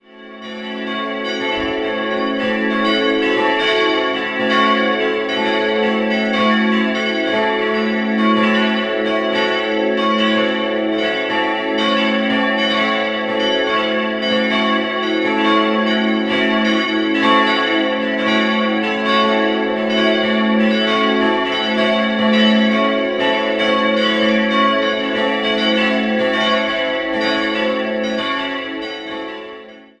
Jahrhundert. 4-stimmiges "Wachet-auf"-Geläut: a'-cis''-e''-fis'' Die Glocken wurden in den Jahren 2009/10 von der Glocken- und Kunstgießerei Lauchhammer hergestellt.